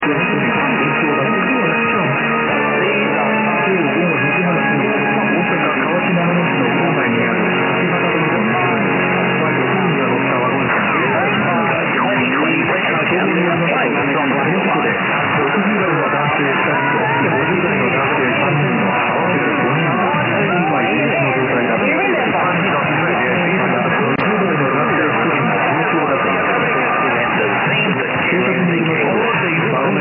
5/29　5月3回目のバイクチョイペに出動。
本日は18時少し過ぎに現地に到着し、もうすっかり慣れたΔFlagと機材を素早く設置。